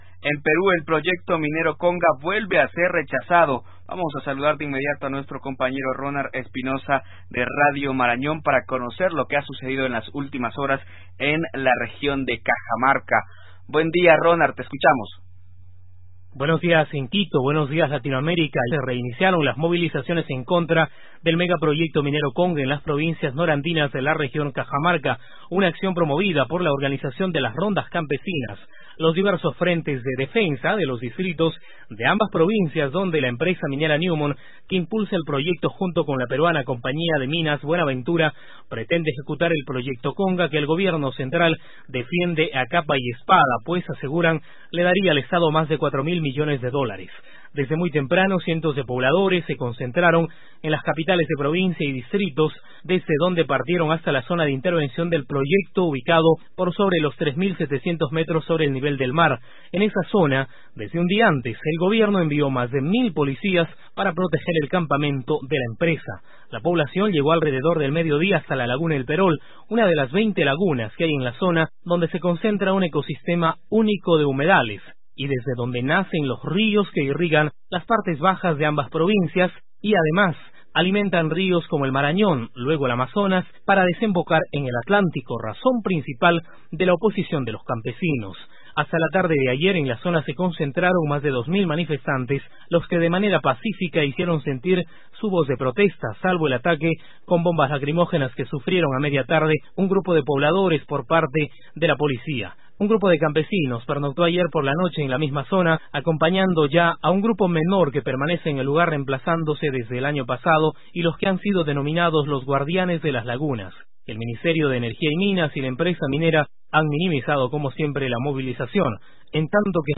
Evalúe usted el siguiente ejemplo de una nota contextuada tomada del programa  Contacto Sur   que emite ALER.